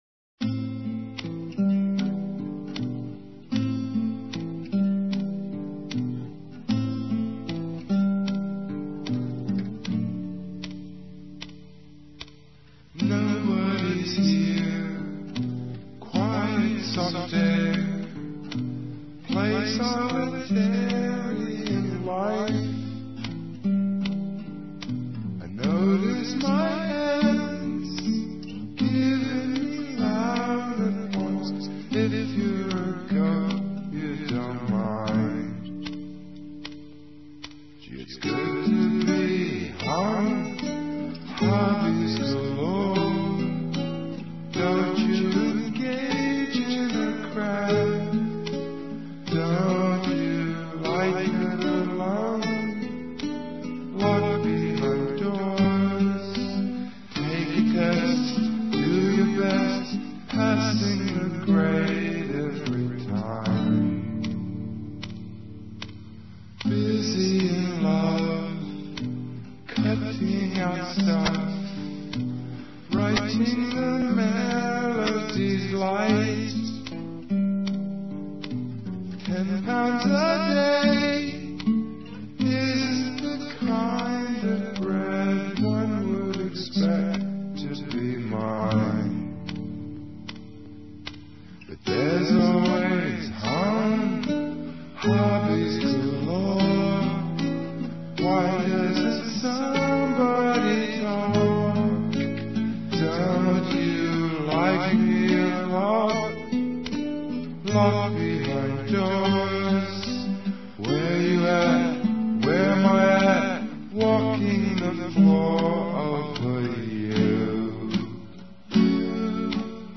ripped to Lo-Fi MP3 Files